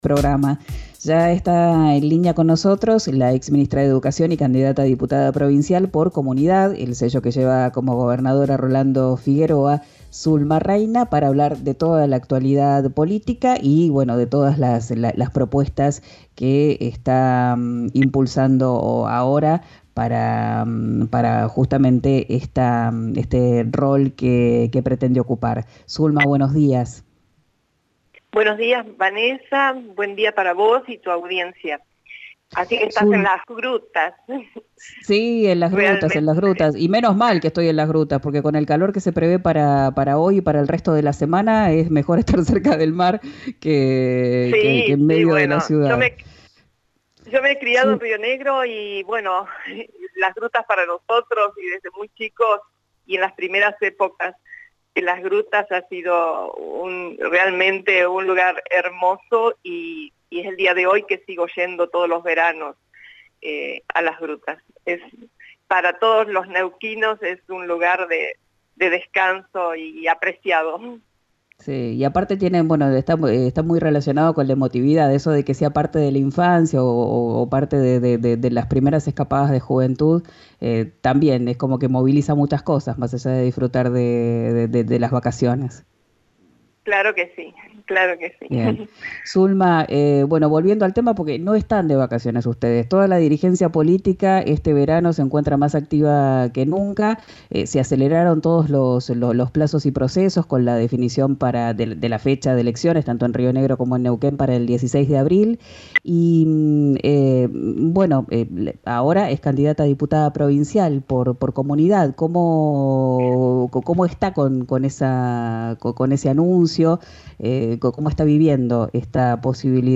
La ex ministra de Educación de Neuquén defendió el espacio conformado por Rolando Figueroa. Habló con RÍO NEGRO RADIO.